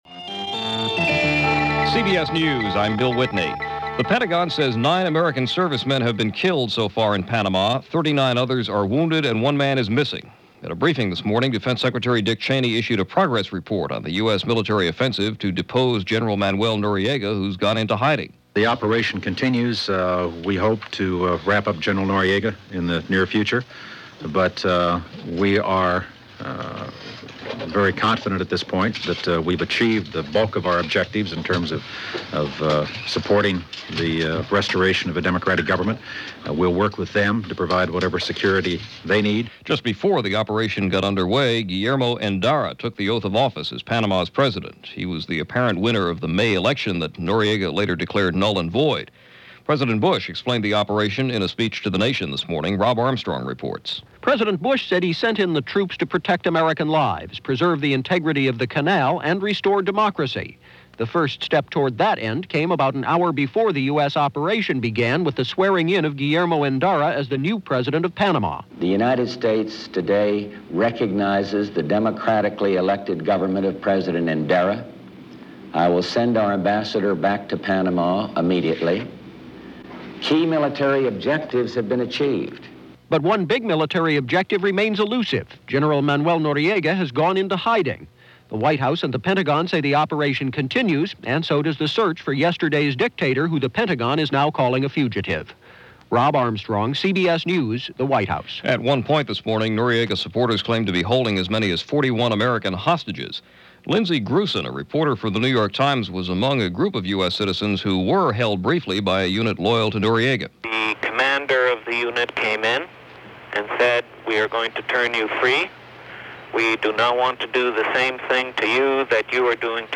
December 20, 1989 – CBS Radio News – reports and bulletins